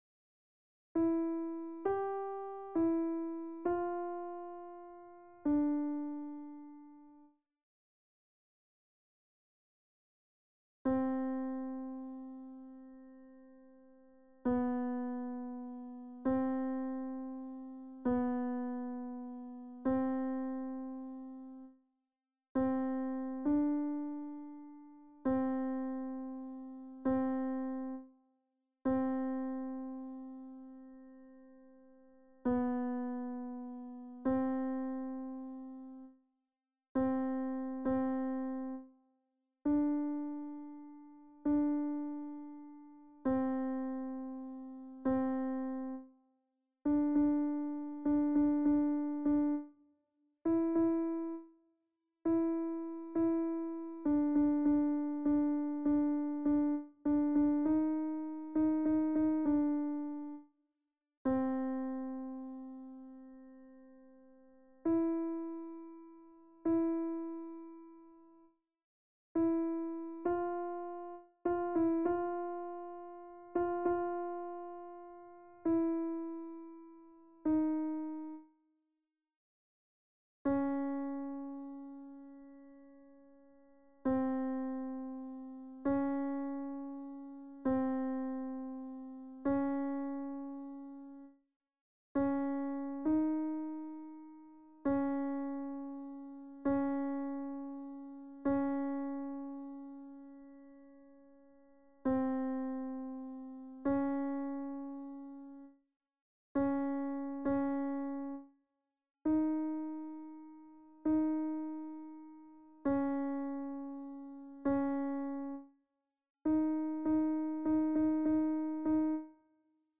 C_est beau la vie – Voix Séparée Alto 2.